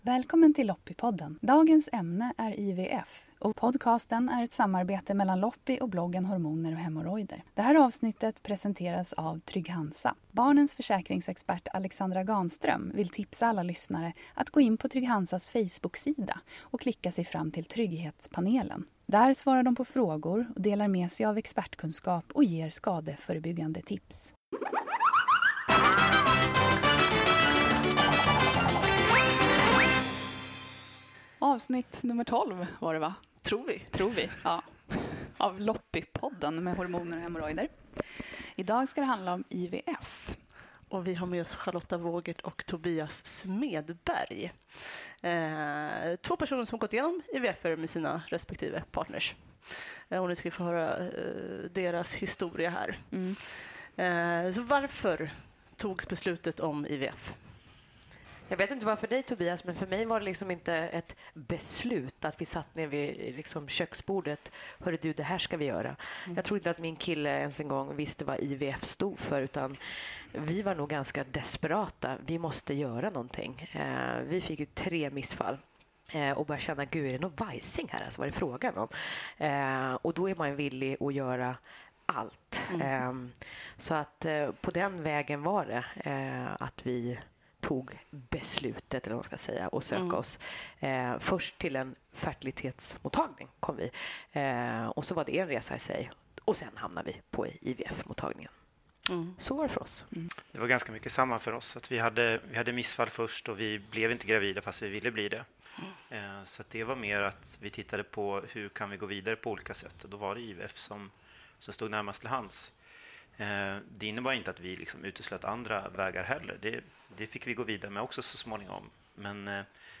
I varje avsnitt intervjuas en gäst som avslöjar sina erfarenheter av småbarnslivet, vardagskaoset och hur man överlever sina första år som förälder.